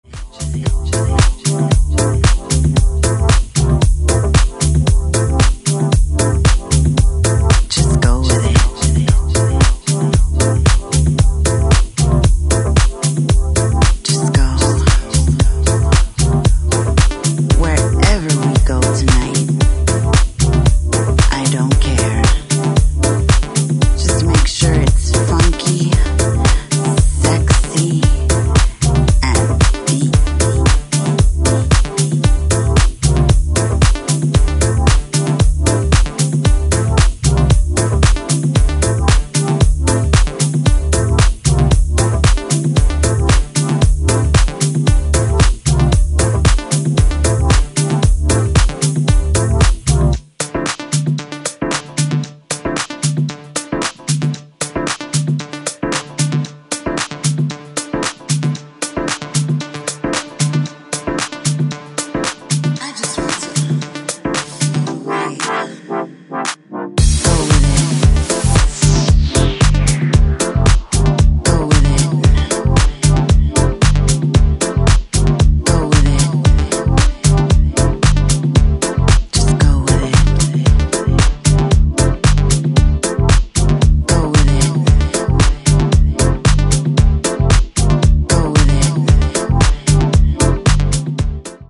ジャンル(スタイル) DEEP HOUSE / RE-EDIT